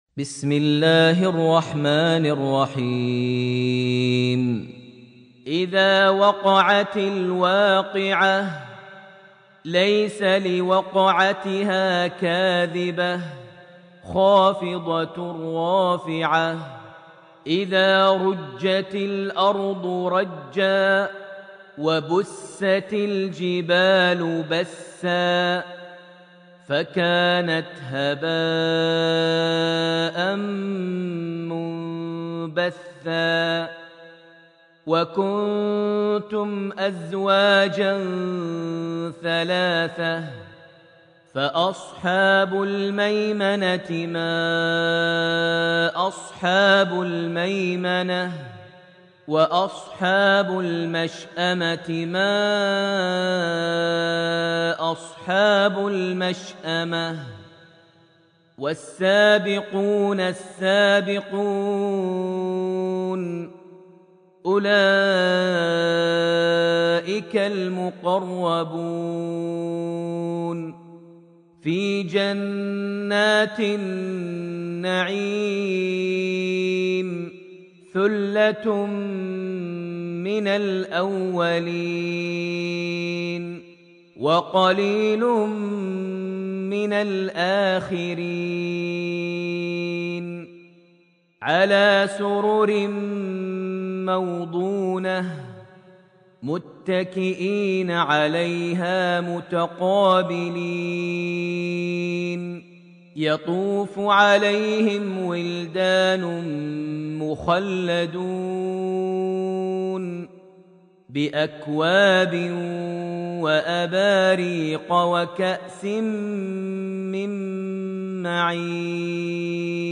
Surat alwaqia > Almushaf > Mushaf - Maher Almuaiqly Recitations